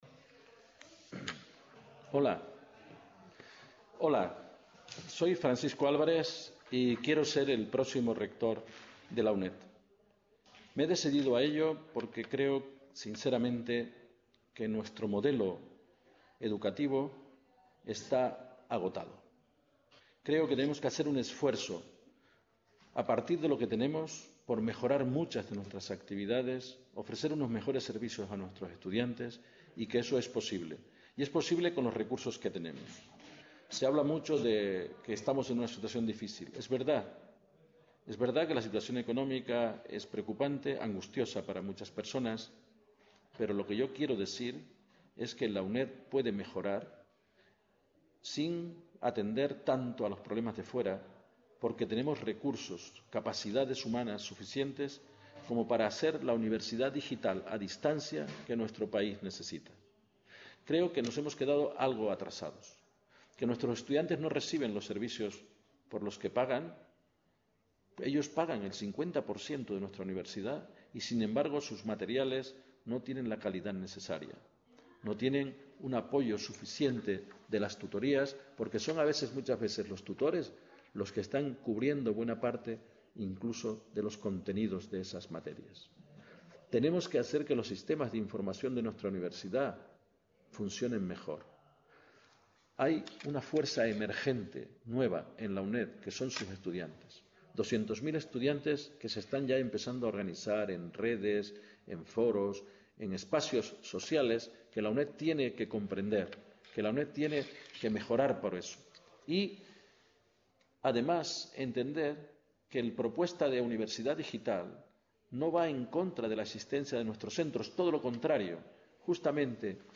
Se emite desde el centro de Nou Barris (Barcelona)